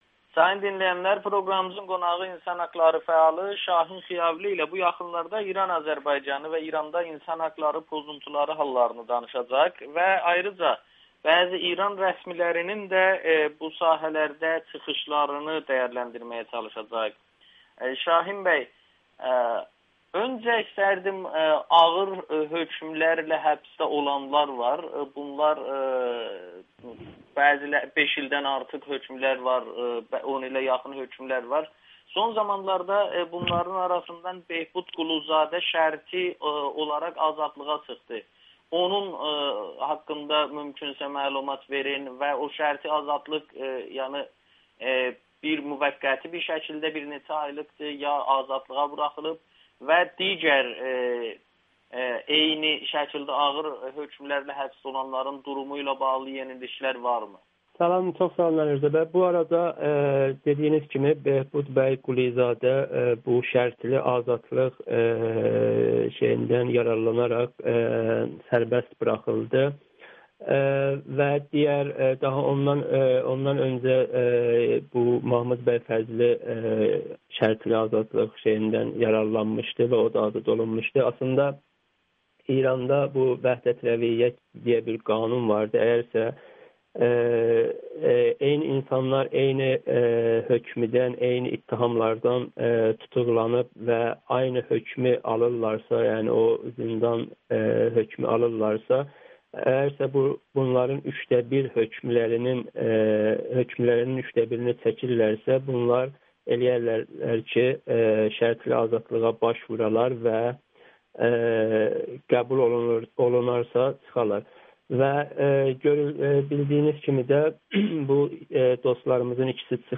Türk fəallara ağır hökmlər vermək üçün casusluq ittihamını ortaya atırlar [Audio-Müsahibə]